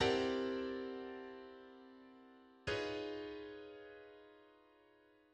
Side-slipping complementation: C7 chord/Lydian dominant scale (chord-scale system) and complement
Side-slipping_complementation.mid.mp3